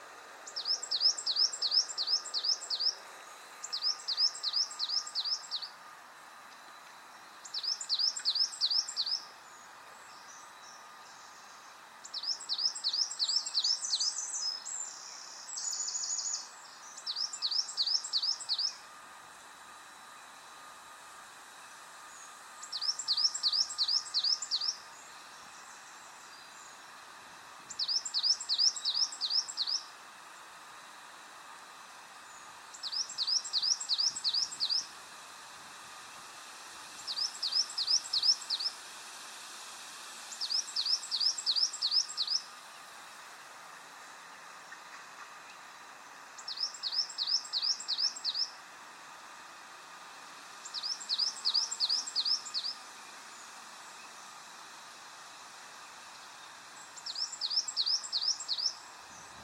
XC713383-chapim-carvoeiro-Periparus-ater